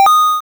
success.wav